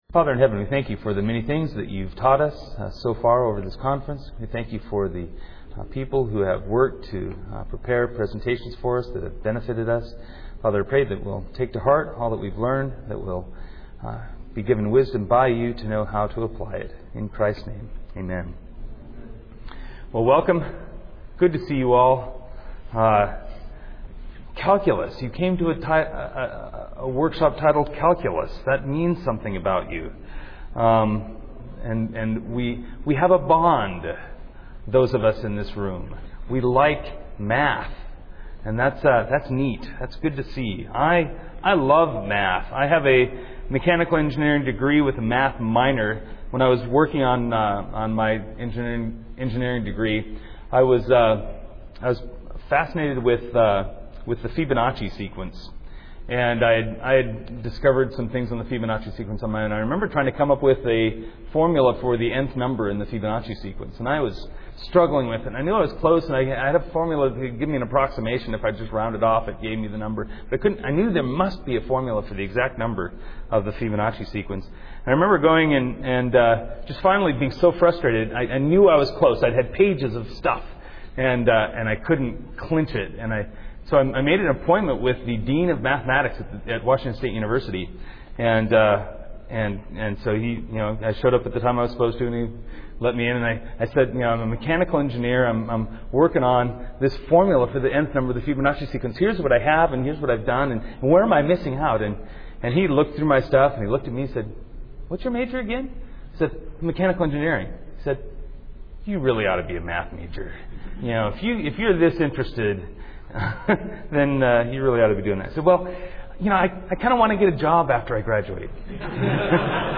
2007 Workshop Talk | 0:29:22 | 7-12, Math
The Association of Classical & Christian Schools presents Repairing the Ruins, the ACCS annual conference, copyright ACCS.